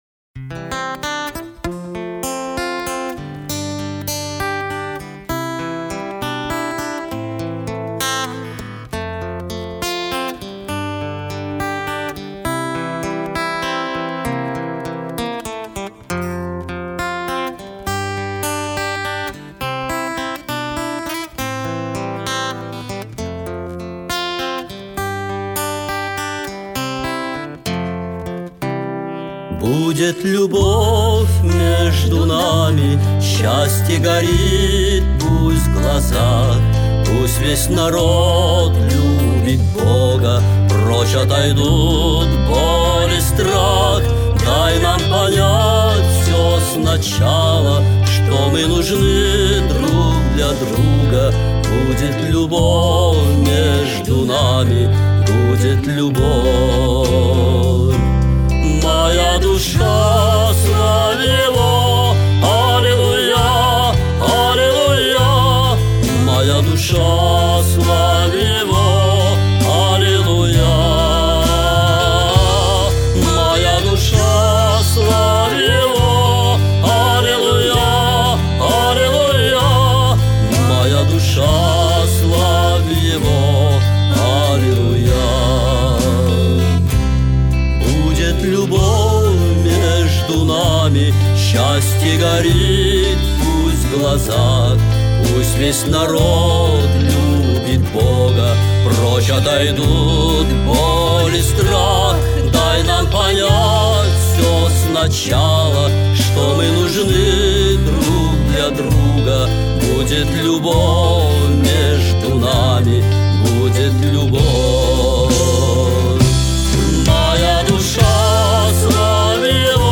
69 просмотров 103 прослушивания 6 скачиваний BPM: 150